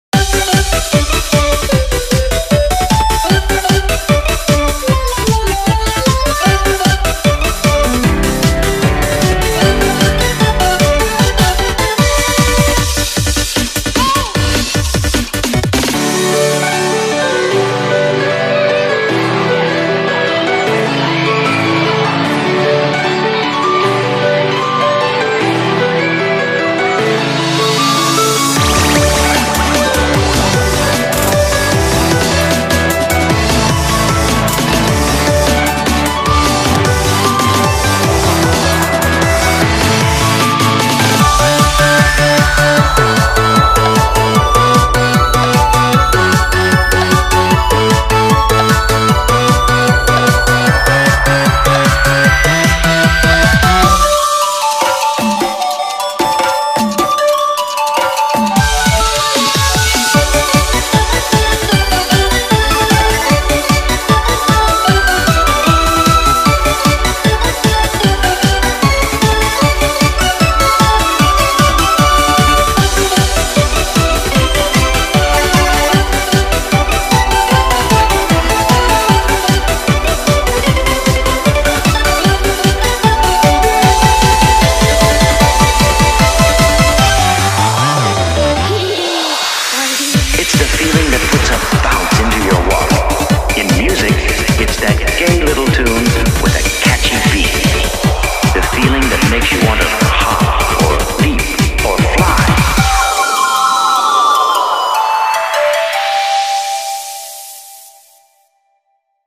BPM152
MP3 QualityMusic Cut